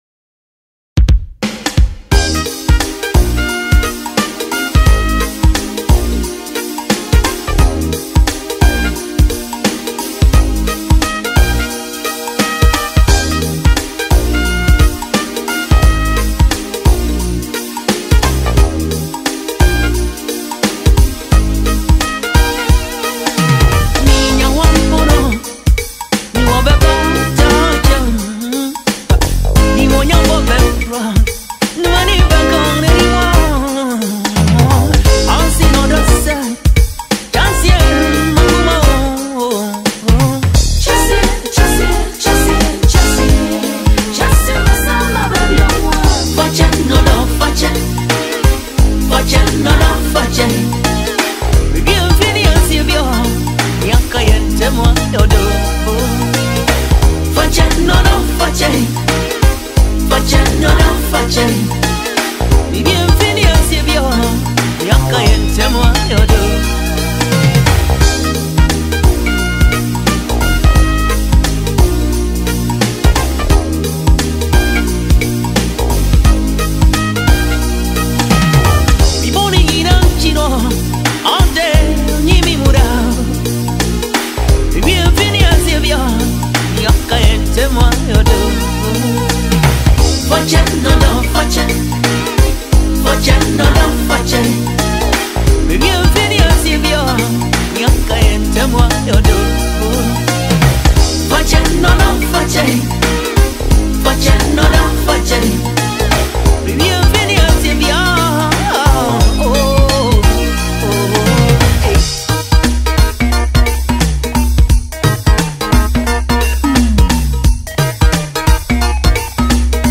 evergreen highlife
Known for his deep lyrics and soulful delivery
The emotional tone